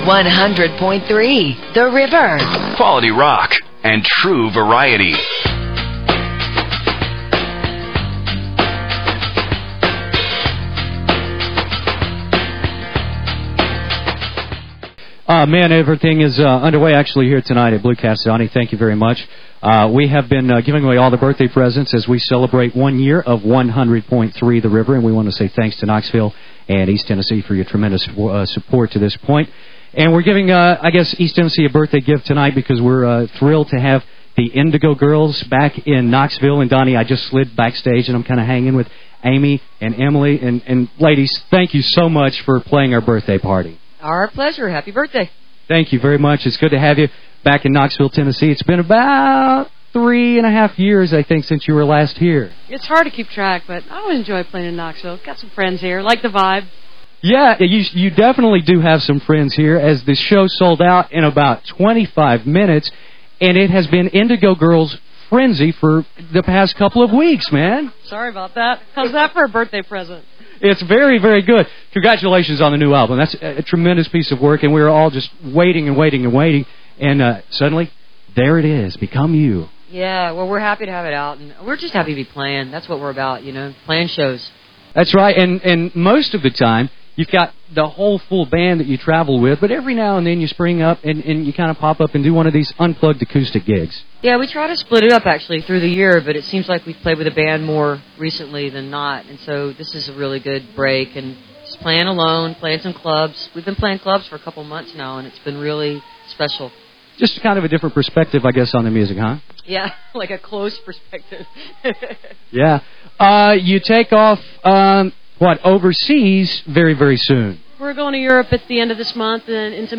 (acoustic duo)
interview